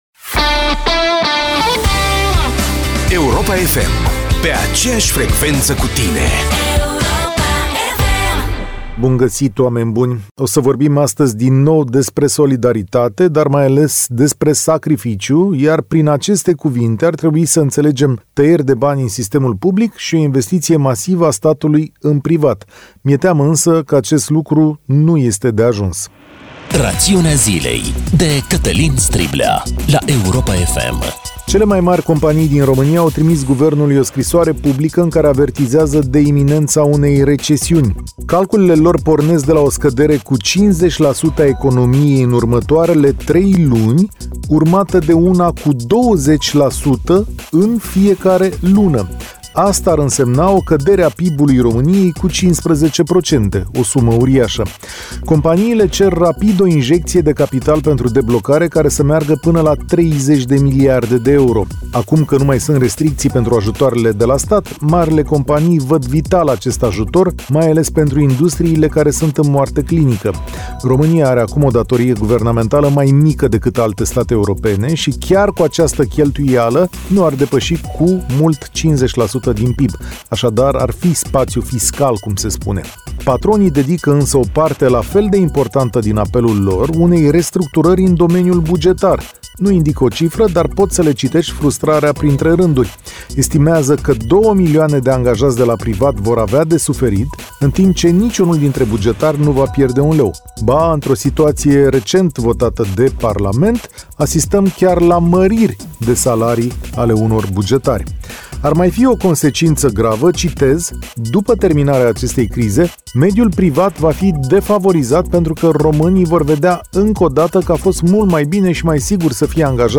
Rubrica Rațiunea Zilei, cu Cătălin Striblea, este de luni până vineri pe frecvențele Europa FM în emisiunile Deșteptarea și România în Direct.